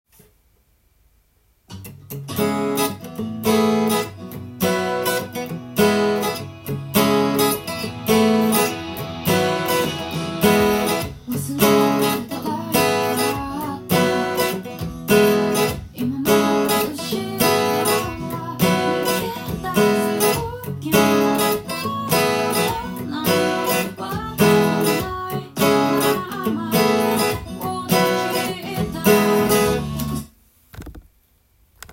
音源にあわせて譜面通り弾いてみました
ブリッジミュートになります。
そうすることズンズンという低音が増し、